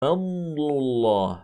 e ـــ Att blanda dess ljud med en del av ljudet av ghunnah, som i: